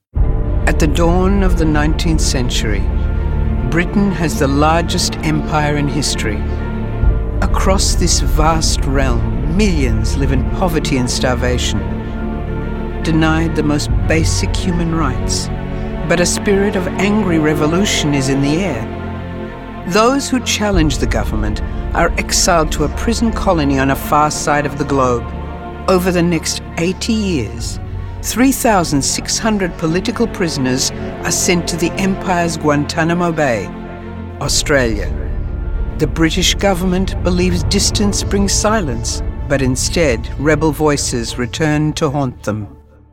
I have a dedicated professionally equipped home studio for high quality sound and quick efficient turn around. My voice overs are confident, warm, conversational, expressive, engaging, versatile and clear.
englisch (australisch)
Sprechprobe: Sonstiges (Muttersprache):